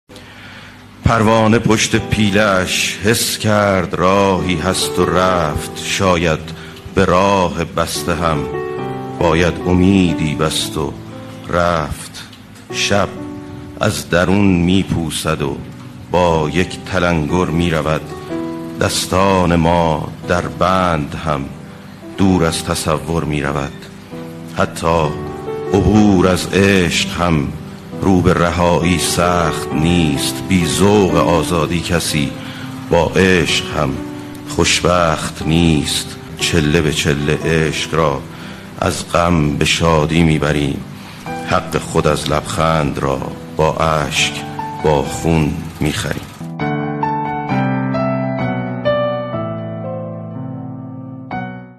دانلود دکلمه پروانه پشت پیله اش با صدای افشین یداللهی
گوینده :   [افشین یداللهی]